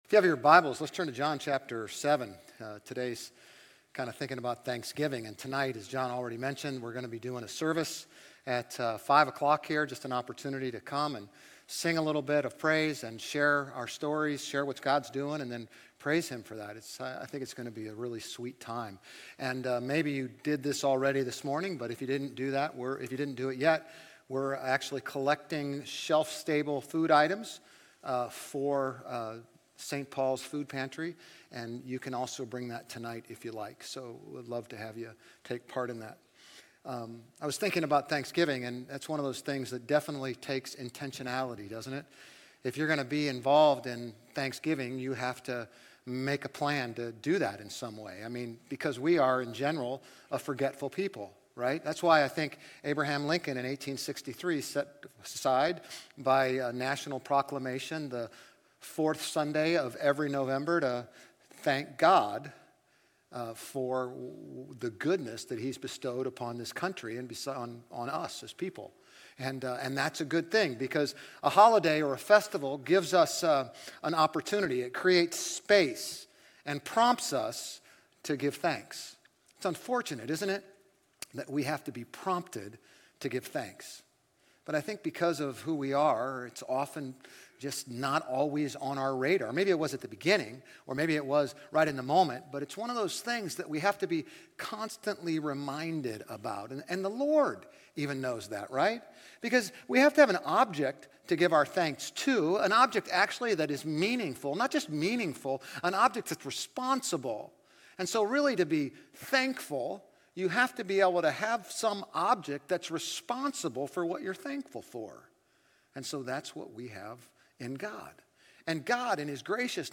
GCC-OJ-November-19-Sermon.mp3